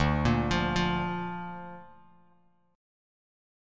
Dm#5 Chord